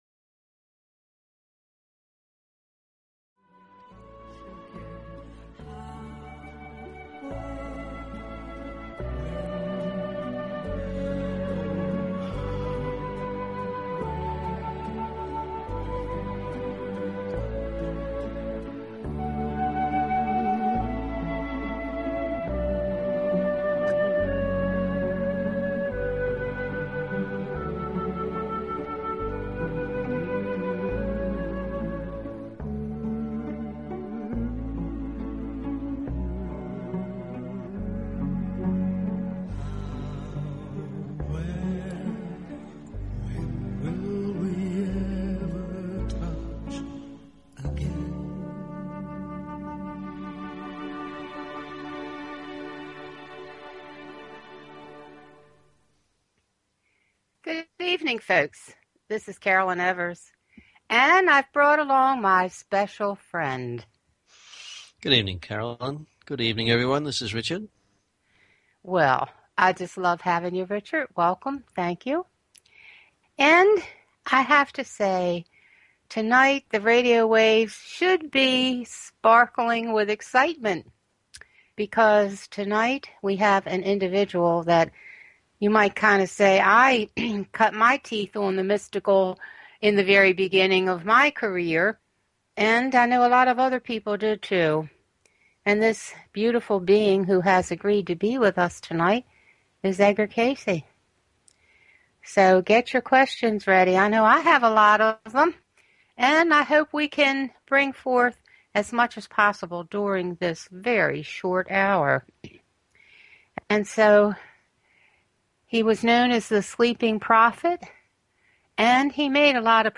Talk Show Episode, Audio Podcast, The_Messenger and Courtesy of BBS Radio on , show guests , about , categorized as